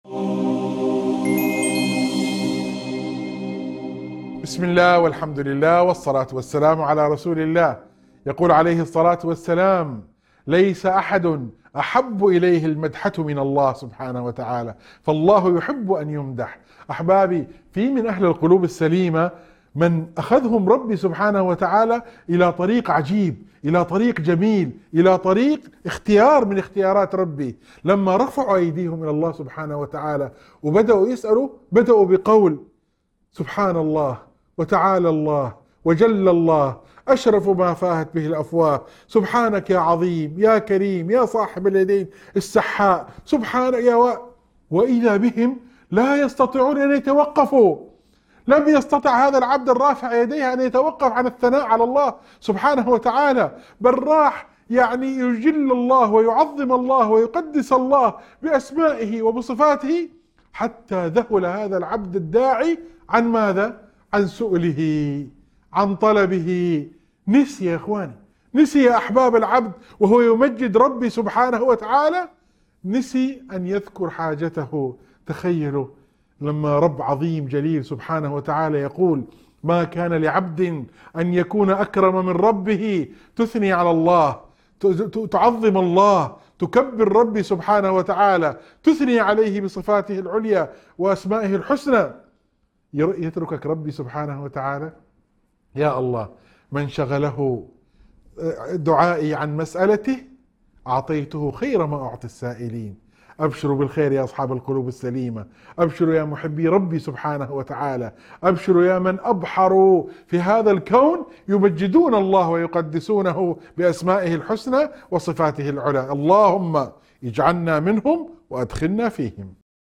موعظة مؤثرة عن محبة الله لمدحه وثناء عباده عليه، وتحفيز للقلوب السليمة على الانشغال بتمجيد الله والتسبيح بأسمائه الحسنى. تشرح كيف أن الانشغال بحمد الله ينسي العبد حاجته ويجعله من المقربين.